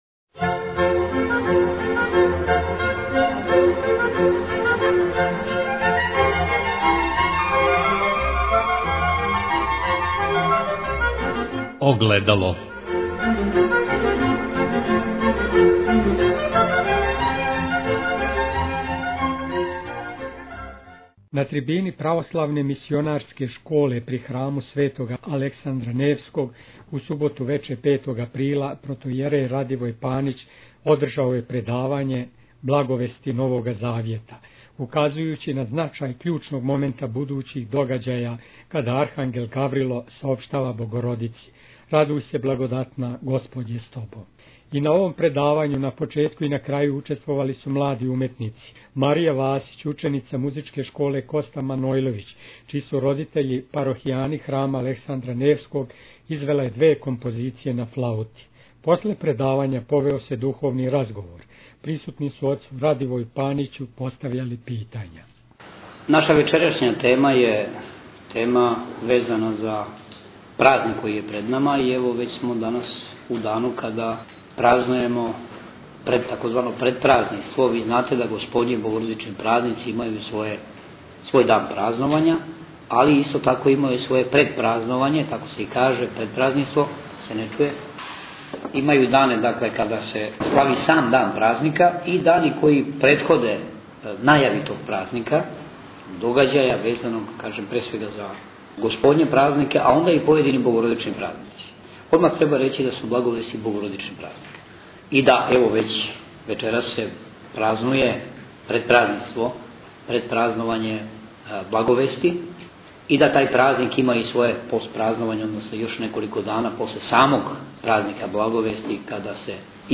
предавања
на трибини православне мисионарске школе, при храму Светог Александра Невског у Београду